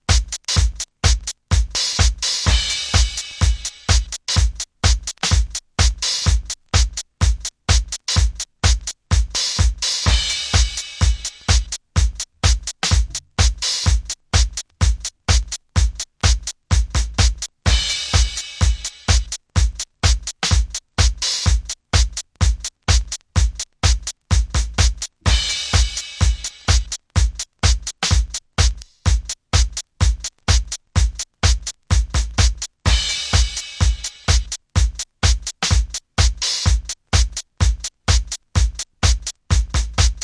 タフに華麗に。